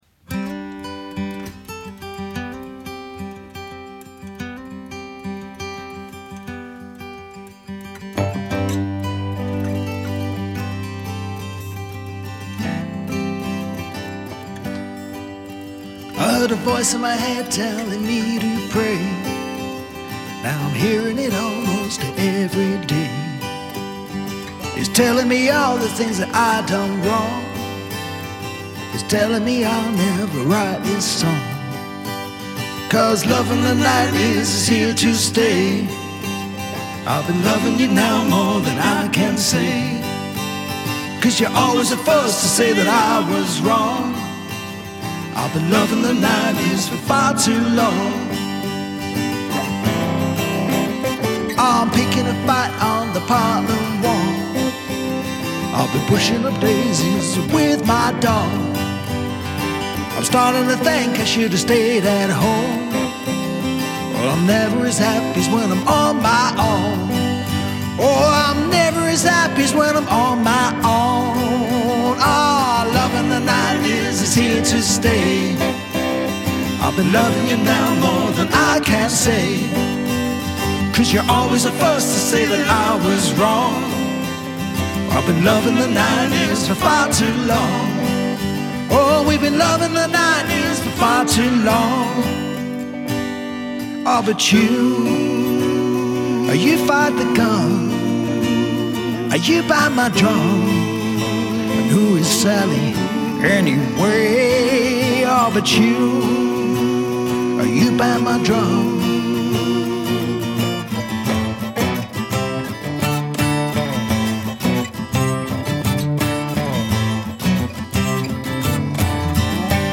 recorded at BBC Scotlant Studios